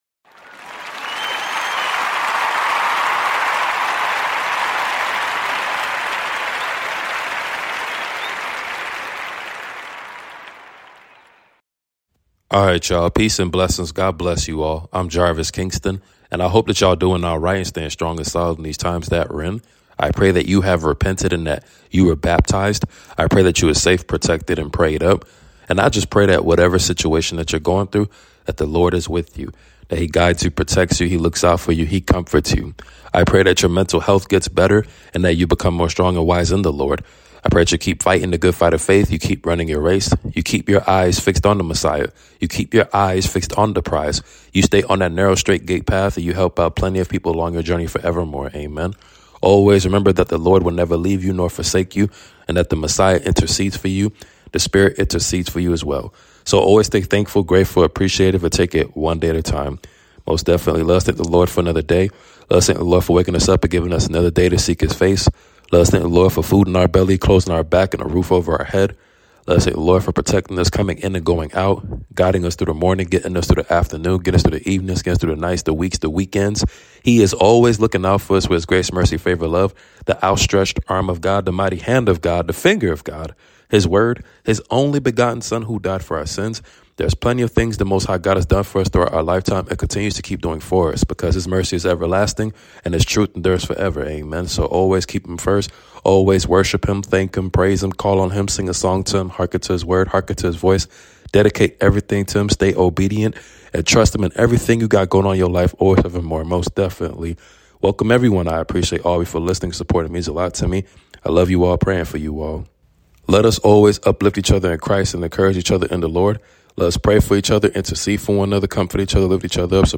Your Nightly Prayer 🙏🏾 Galatians 6:9